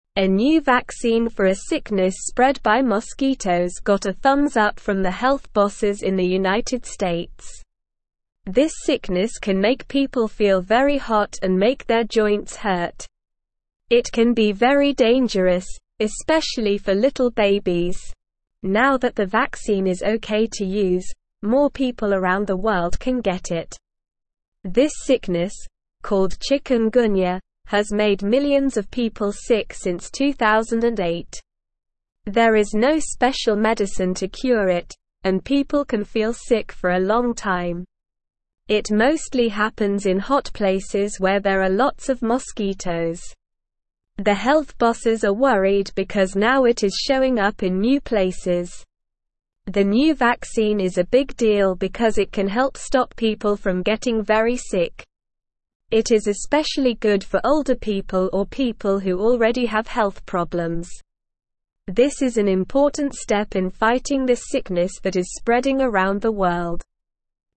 Slow
English-Newsroom-Lower-Intermediate-SLOW-Reading-New-shot-to-stop-bad-bug-bite-sickness.mp3